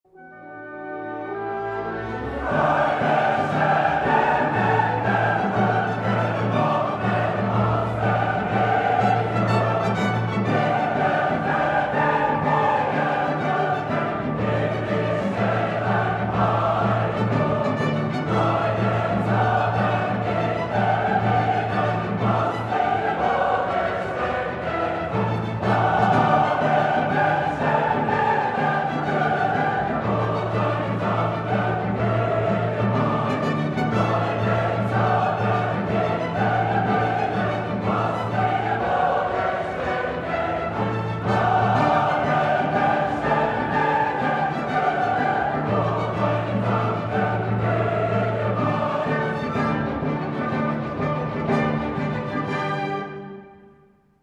ミレニアム特別演奏会より
２０００年１２月１０日 東京文化会館大ホール